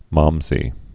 (mämzē)